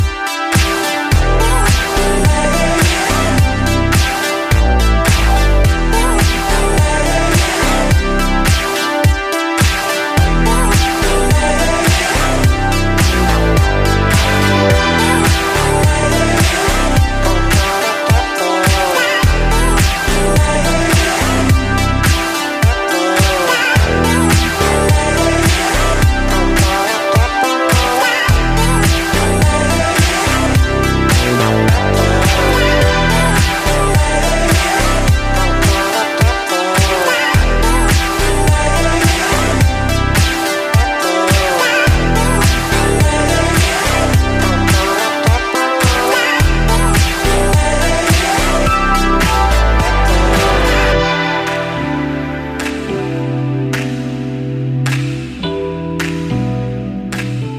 Balearic-Sunset Disco